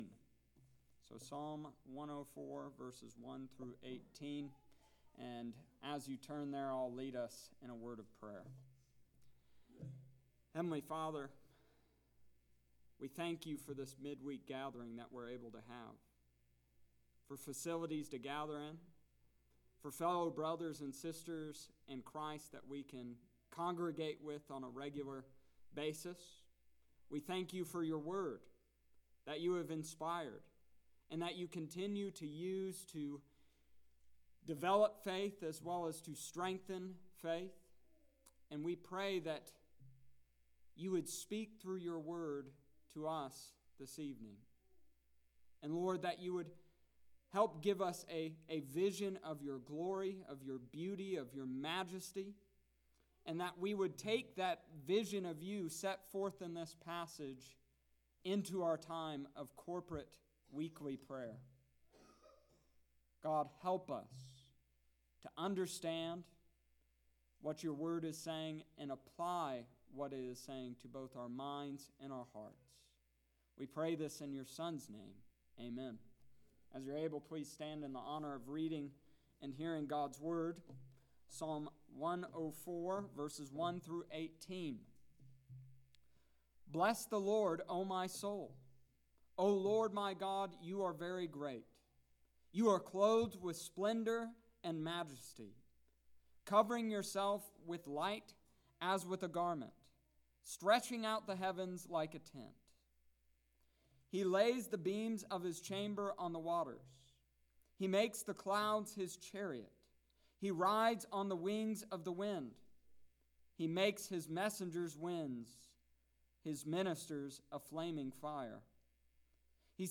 Wednesday Night Service June 10th, 2020 Psalm 104 Verses 1-18.